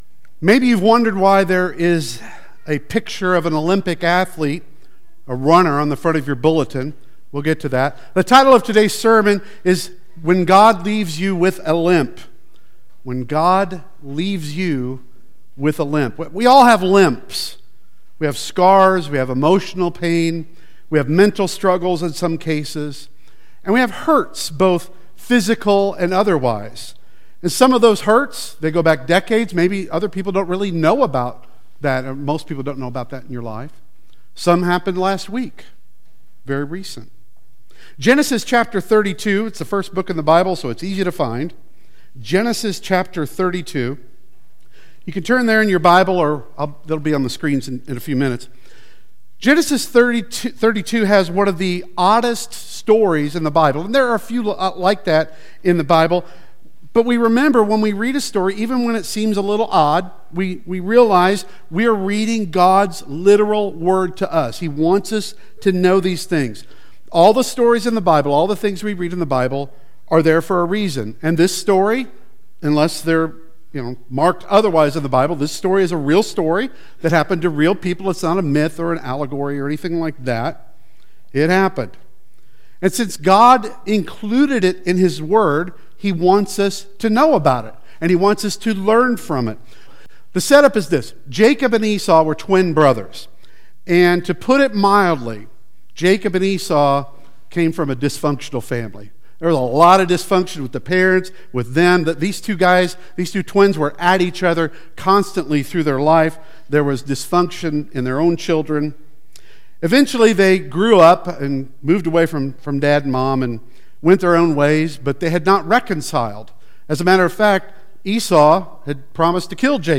Hosea 12.3-5 Service Type: Sunday Worship Service Bible Text